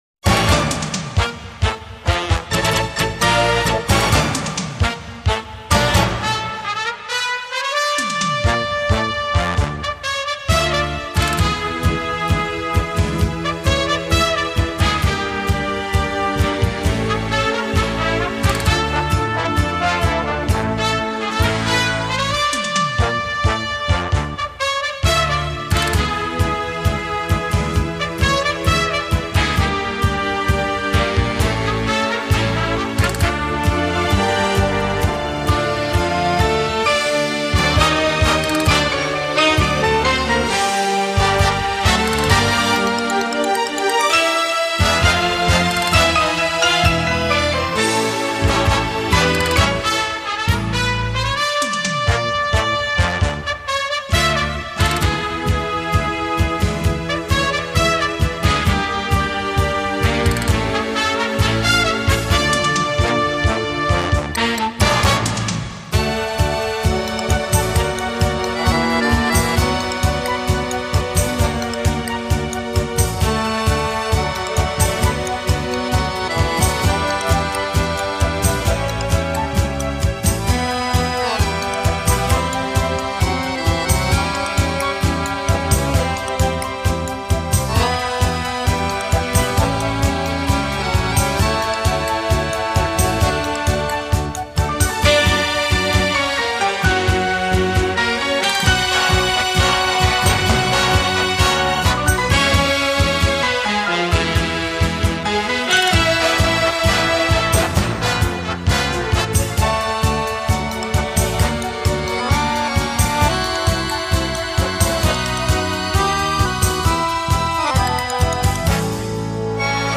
只知道他们是德国的十大跳舞乐队之一。